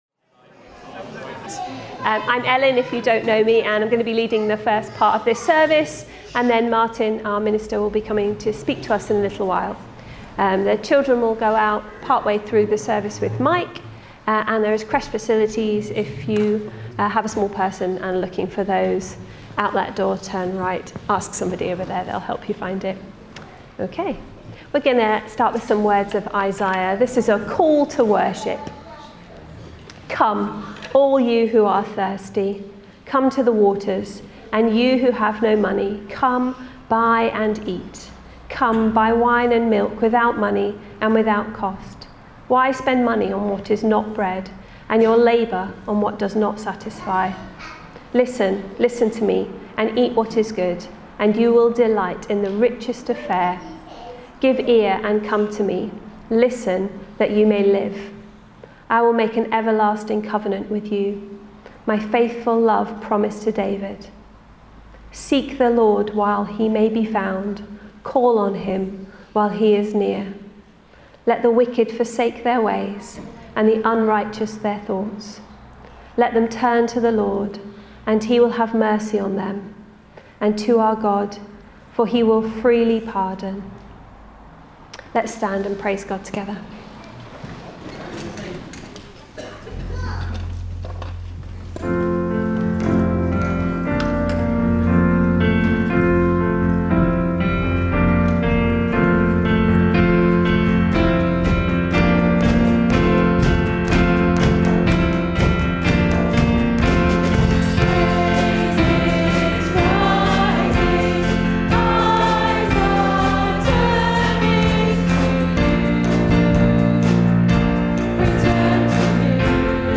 10 August 2025 – Morning Service